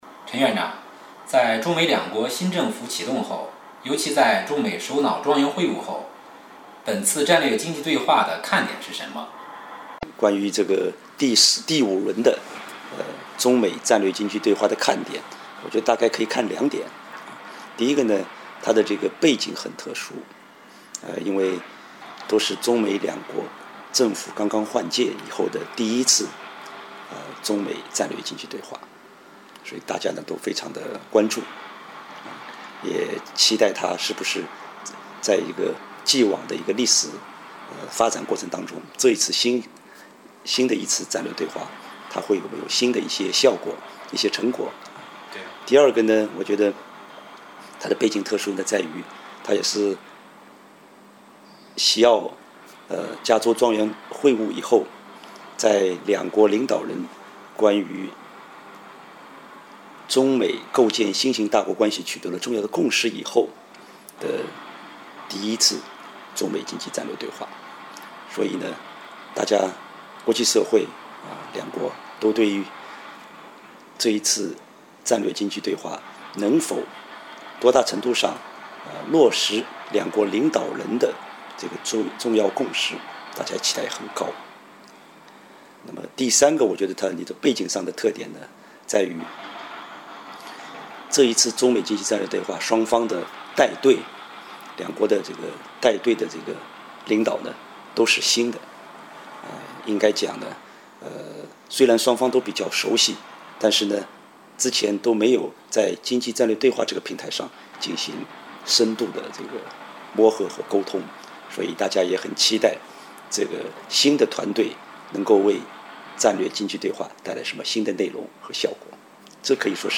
专家访谈：落实中美元首会晤精神的重要对话 2013-07-10 7月10日至11日，第五轮中美战略经济对话在华盛顿举行。